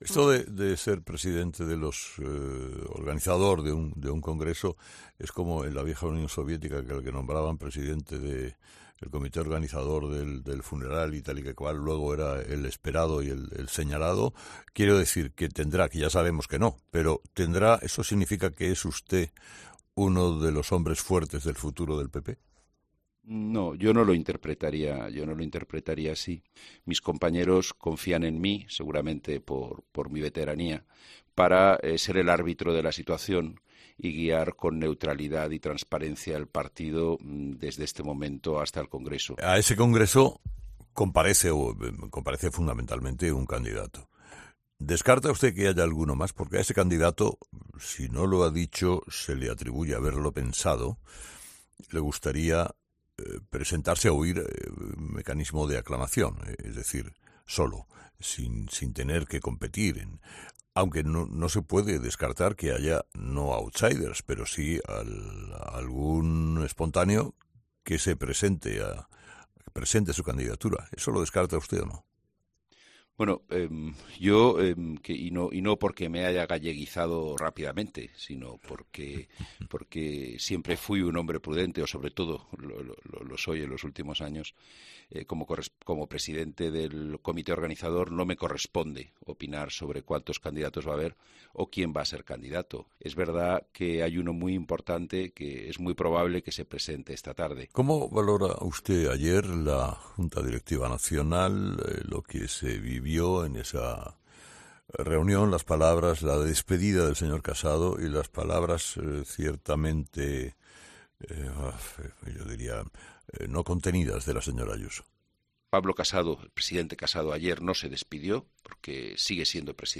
González Pons ha pasado esta mañana por los micrófonos de ‘Herrera en COPE’ en una entrevista en la que ha hablado de todo o casi todo. De Feijóo, Pablo Casado e Isabel Díaz Ayuso.